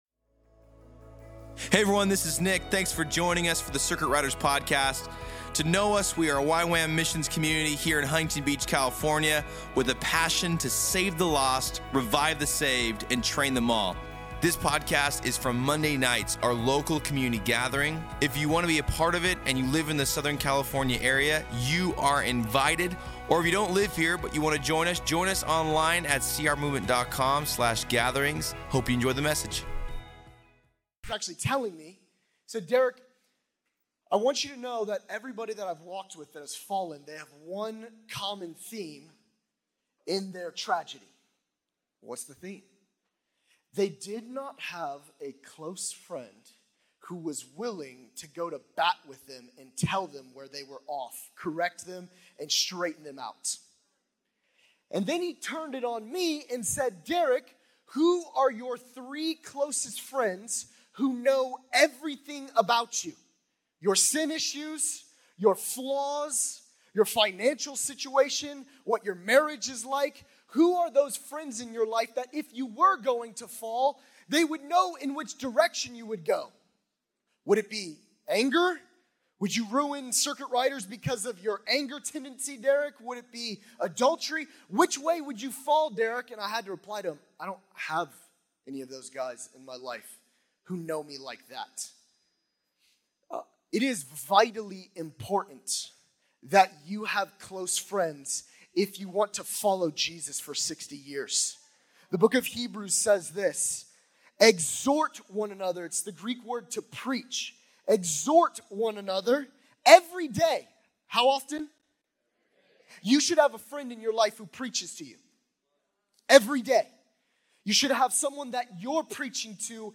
At Circuit Riders Mondays on June 17th 2024.